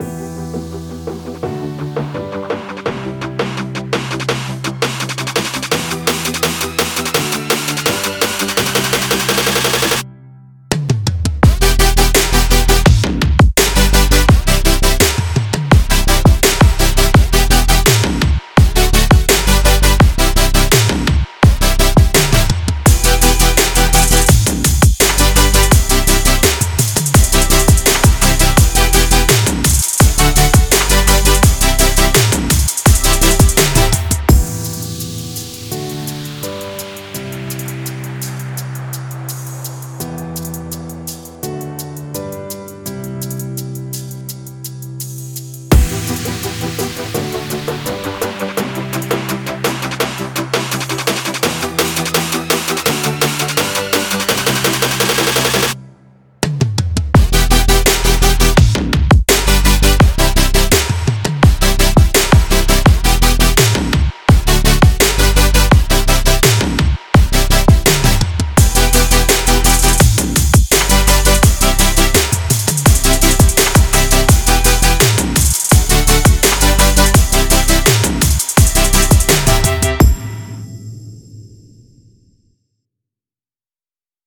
Genre: futurebass, electronic.